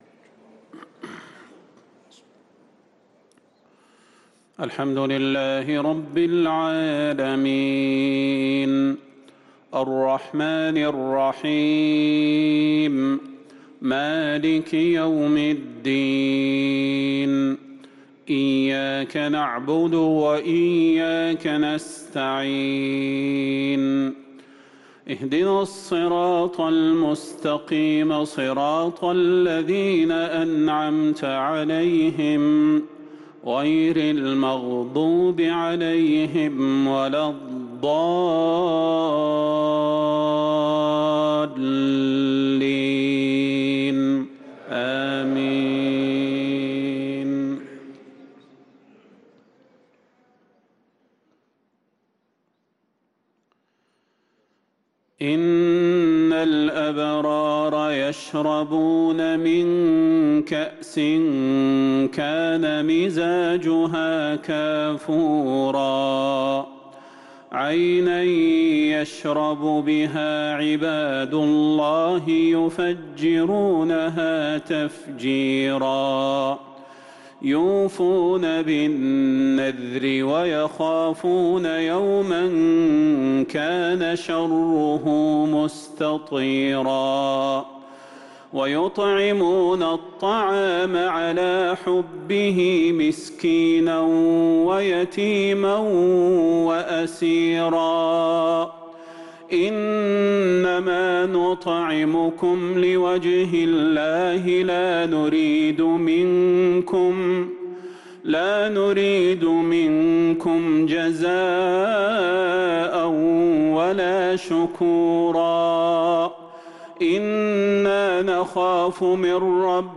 صلاة المغرب للقارئ صلاح البدير 5 ذو الحجة 1444 هـ